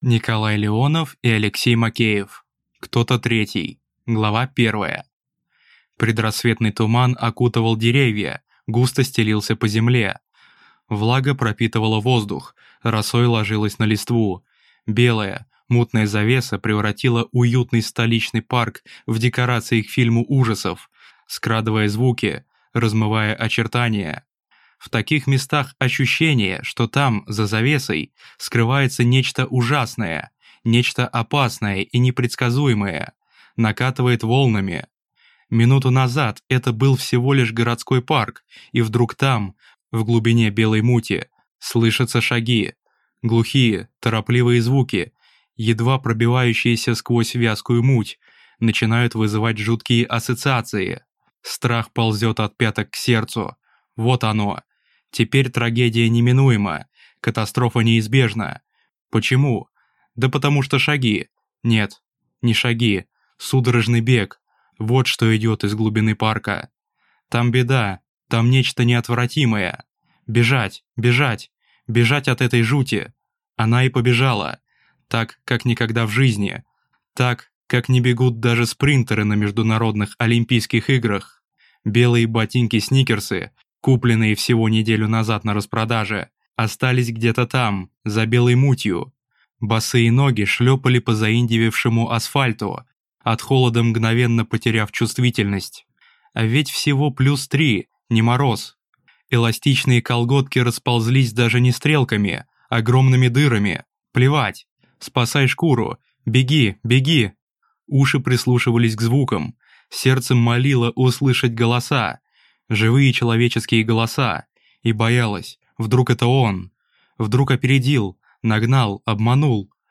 Аудиокнига Кто-то третий | Библиотека аудиокниг